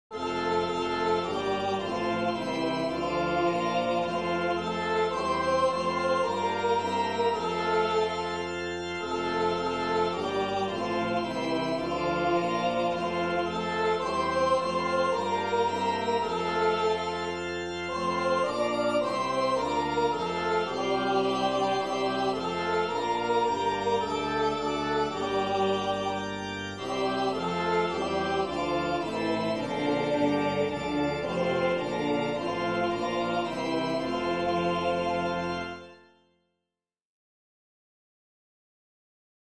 Salmer og musikk ved vielse
655-melodi